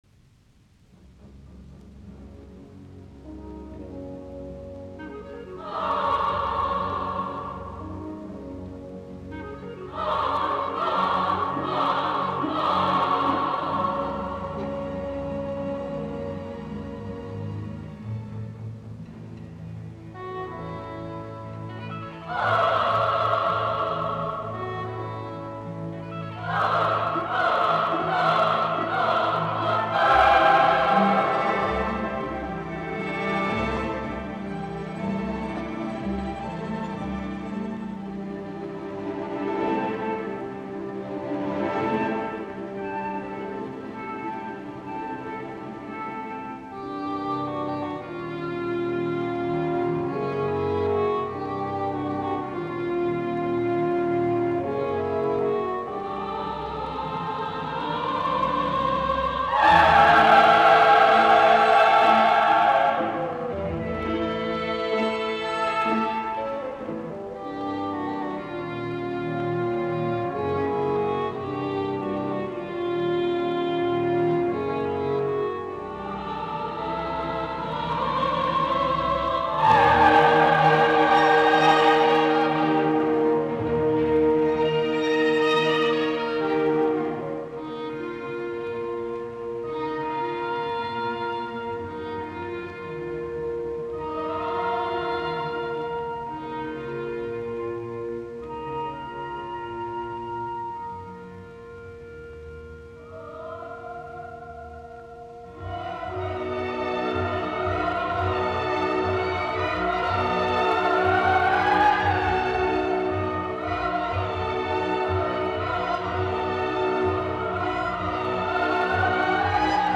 naiskuoro, ork.
Soitinnus: Ork.